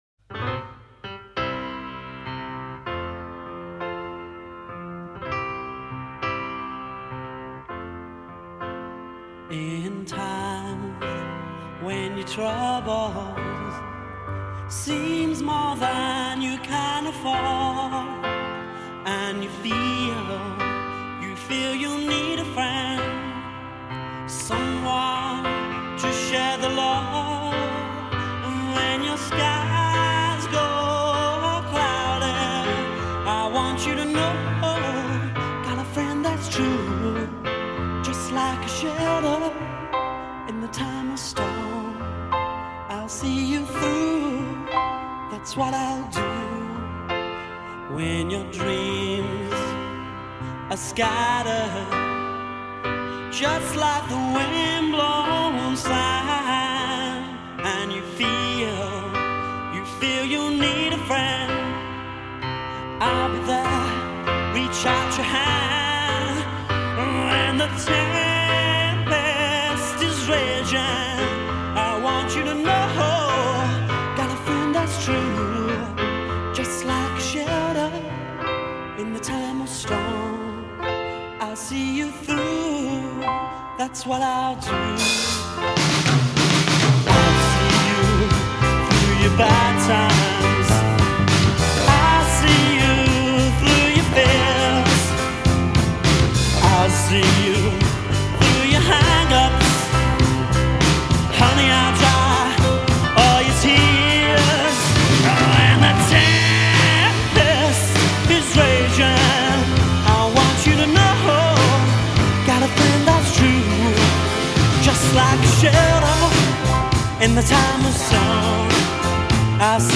faithful, garage-band gospel take
Let me hear the choir!